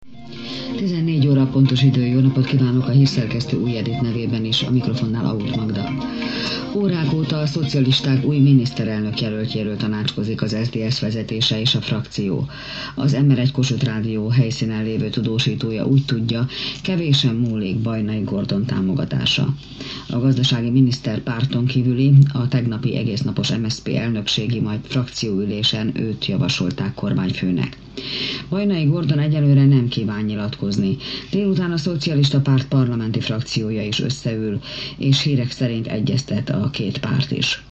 I hear a lot of /sh/.